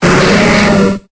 Cri de Smogo dans Pokémon Épée et Bouclier.